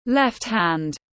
Tay trái tiếng anh gọi là left hand, phiên âm tiếng anh đọc là /left hænd/.
Left hand /left hænd/